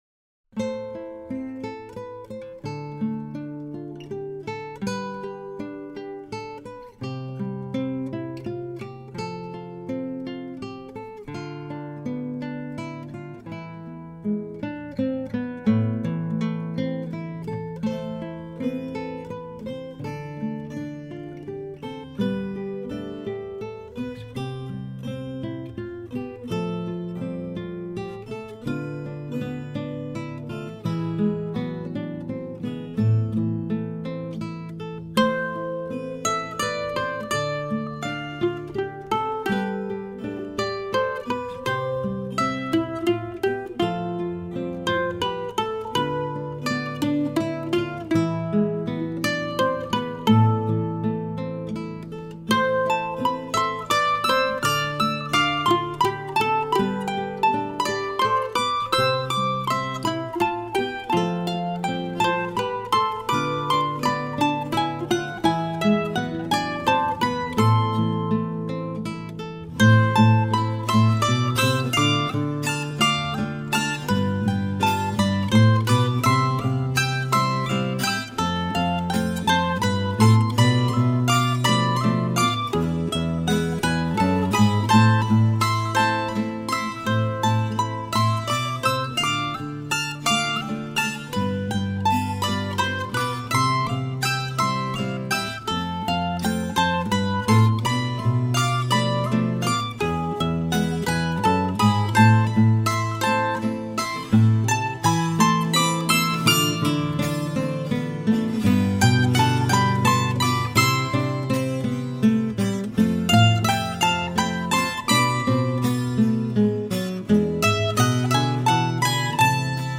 Colombiana